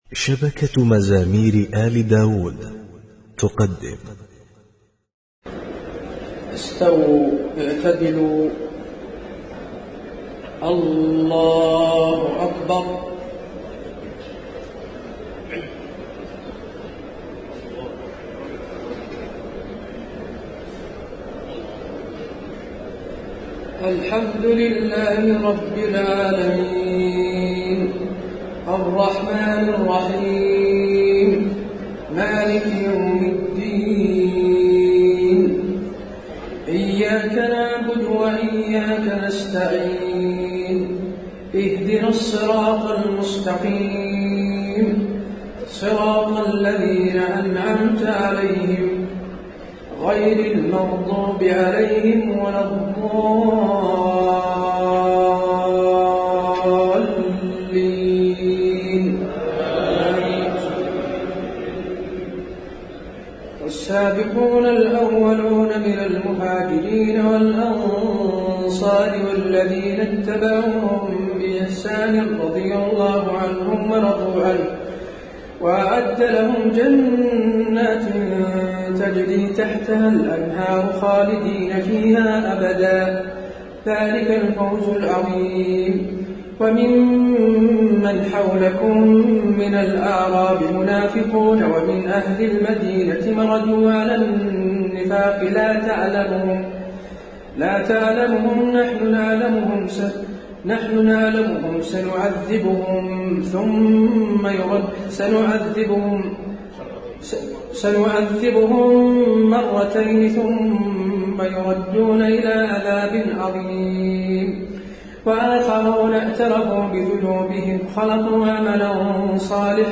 تهجد ليلة 29 رمضان 1432هـ من سورتي التوبة (100-129) و يونس (1-70) Tahajjud 29 st night Ramadan 1432H from Surah At-Tawba and Yunus > تراويح الحرم النبوي عام 1432 🕌 > التراويح - تلاوات الحرمين